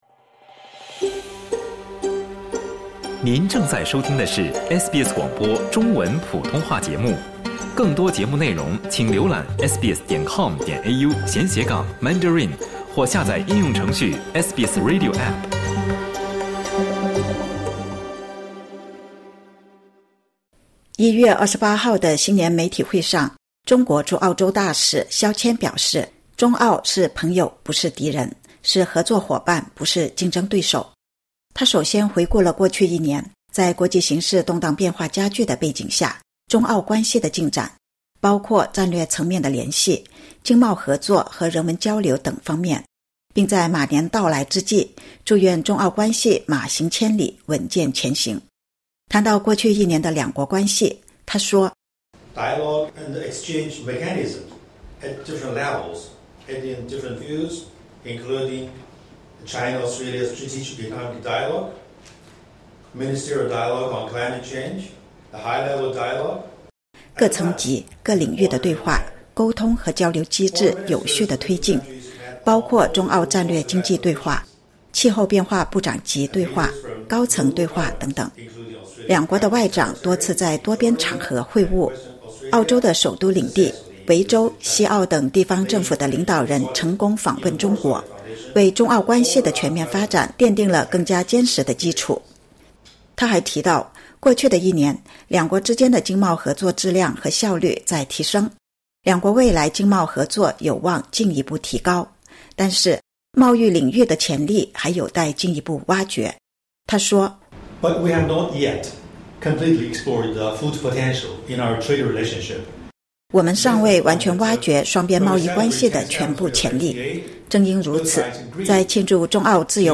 1月28日的新年媒体会上，中国驻澳大利亚大使肖千表示，过去一年，中澳政治互信不断深化、经贸合作质量和效率提升。